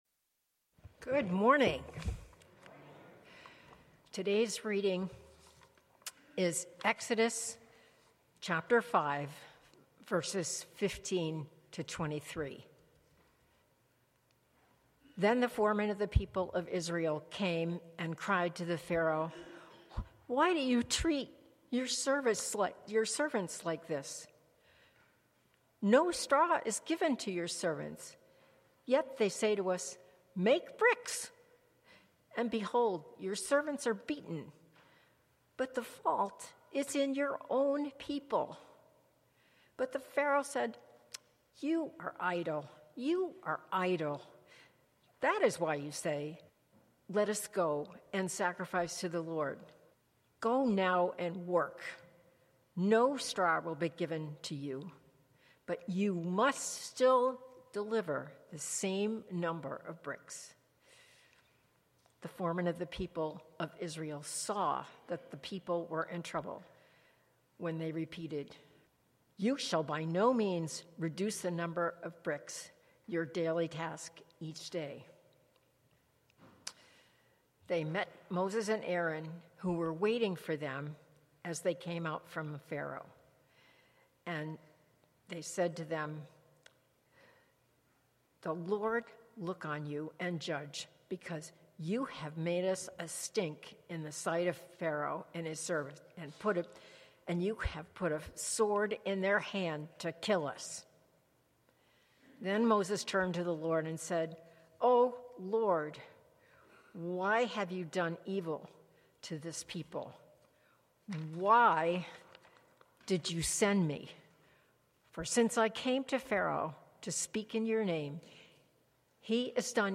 Passage: Exodus 5:22-23 Sermon